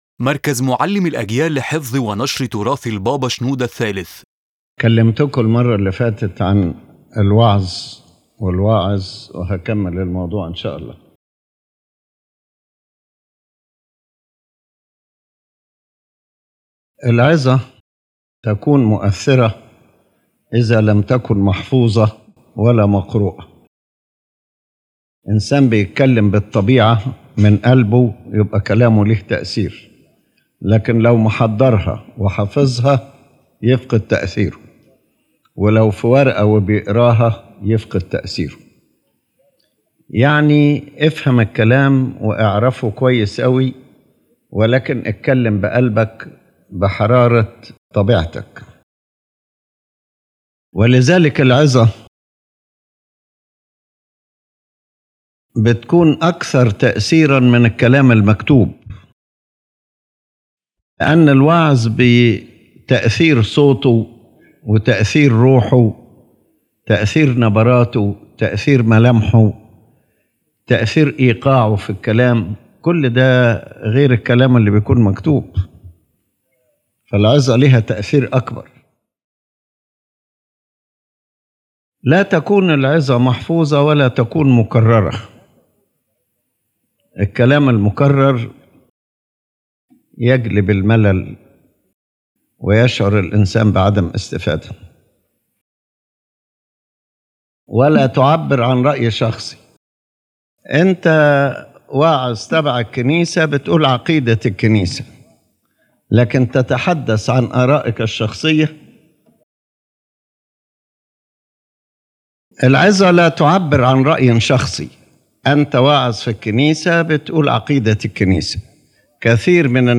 In this lecture, His Holiness Pope Shenouda III continues speaking about preaching and the preacher, emphasizing that a true sermon is not rigidly read nor memorized, but comes out from the heart with sincere and warm spirit.